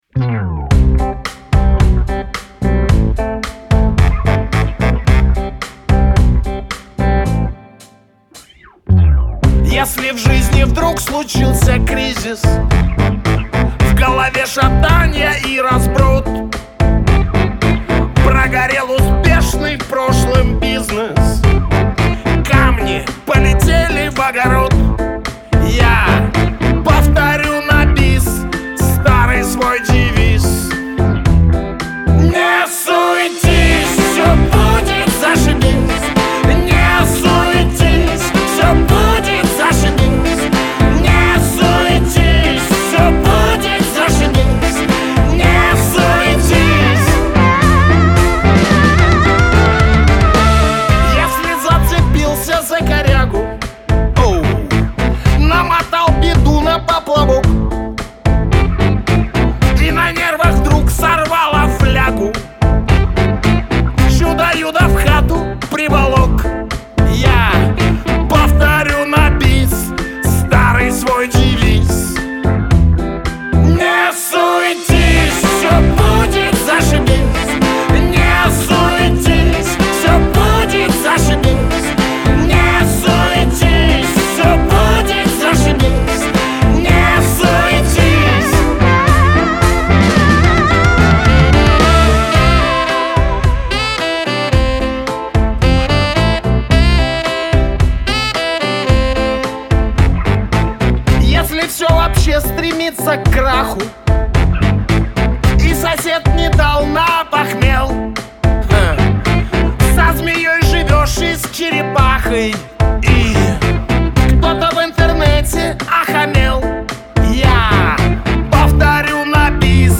pop , Веселая музыка , эстрада